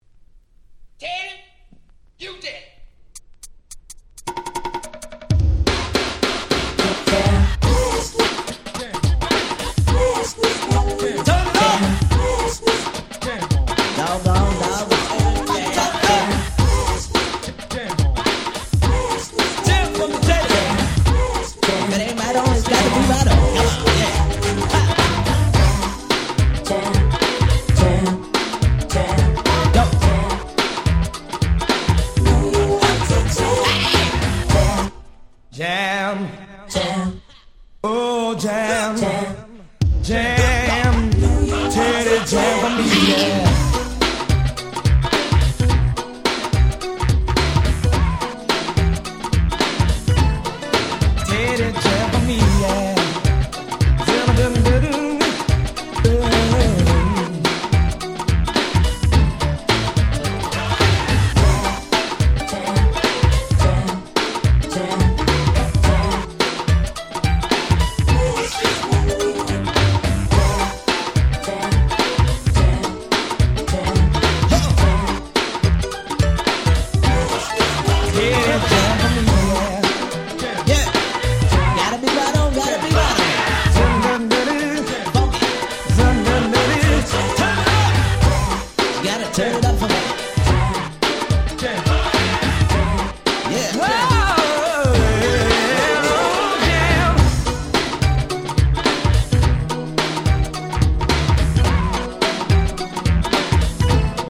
NJS ニュージャックスィング ハネ系 90's